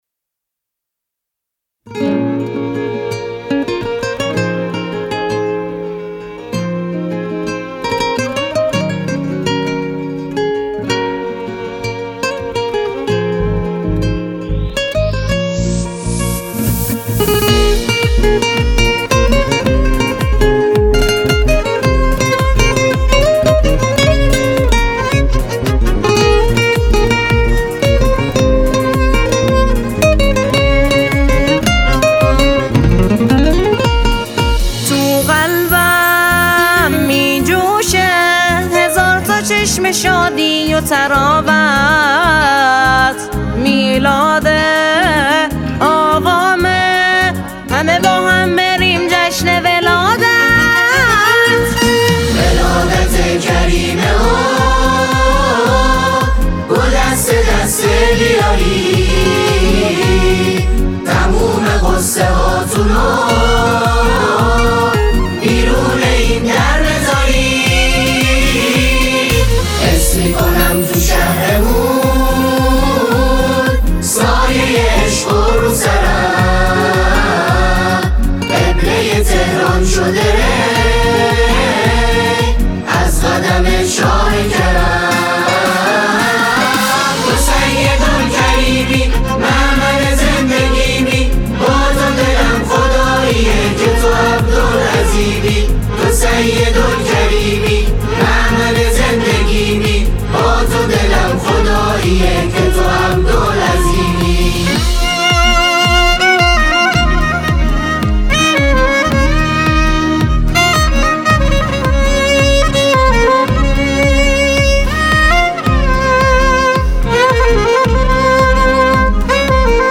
اجرای جمعی از گروه های سرود شهرری - تهیه شده در استودیو